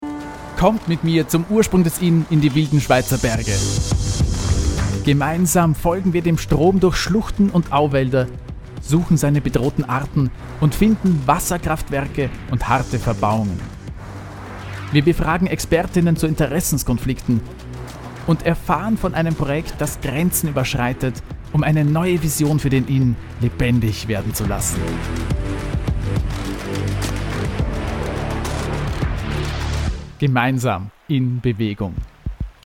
Wach, lebendig, resonierend, anpassungsfĂ€hig, dynamisch, gelassen
Sprechprobe: Industrie (Muttersprache):